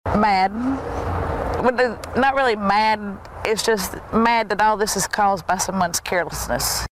THIS NORTH CAROLINA RESIDENT SPOKE ABOUT HOW SHE FEELS ABOUT THE FIRES….